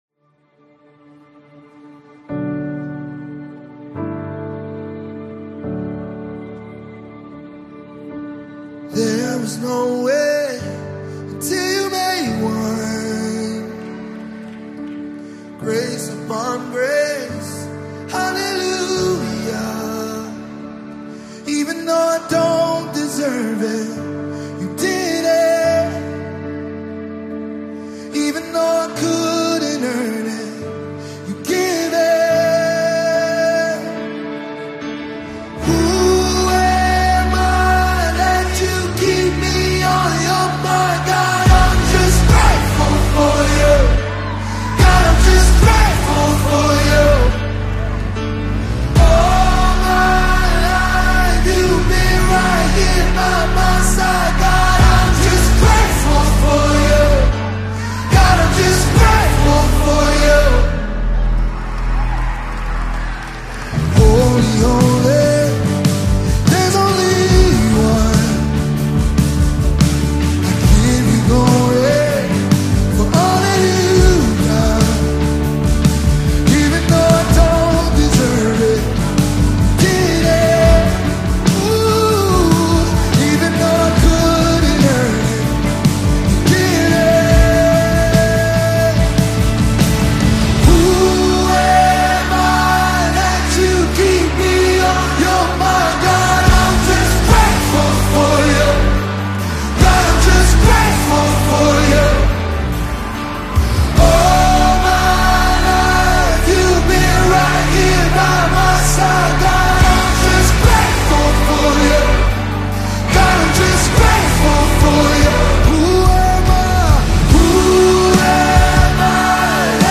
worship anthem
Gospel Songs